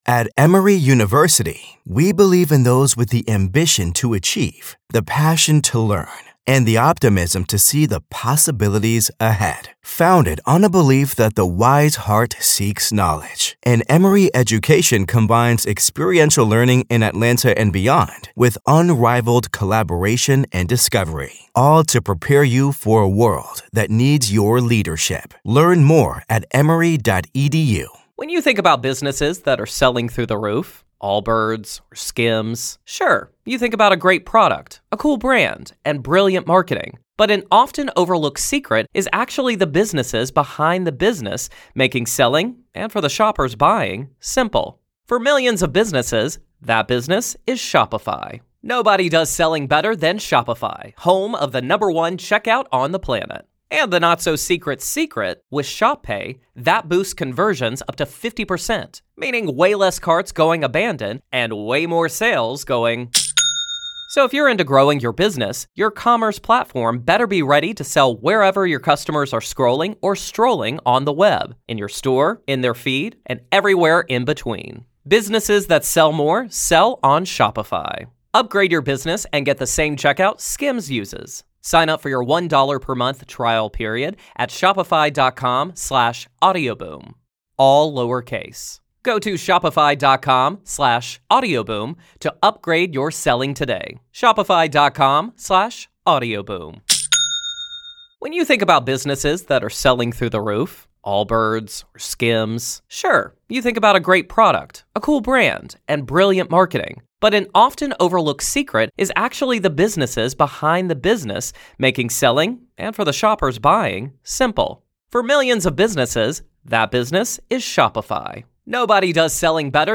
Expert Weighs In